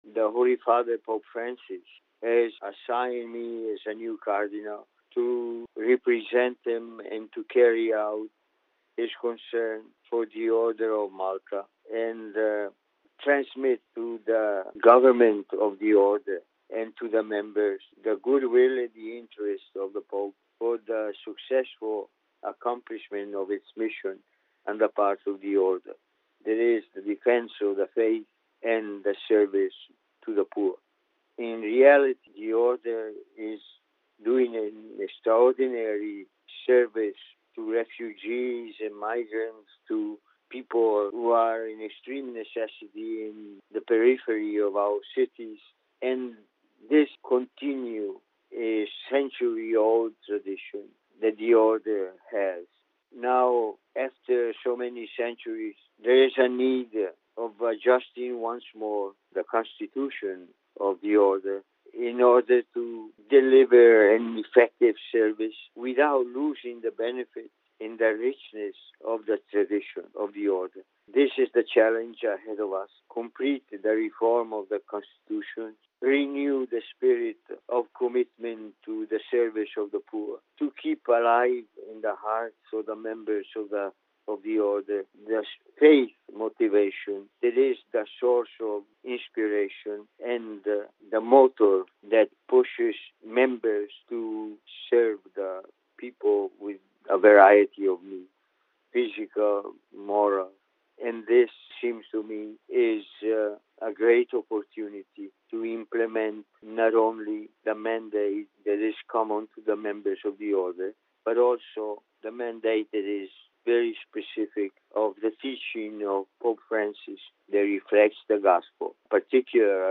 In an interview with Vatican Radio, Cardinal-designate Tomasi speaks about his new ministry: Interview with Cardinal-designate Tomasi The Sovereign Order of Malta is a lay religious order of the Catholic Church since 1113 and a subject of international law.
Interview-with-Cardinal-designate-Tomasi.mp3